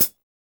SLOP PDL.wav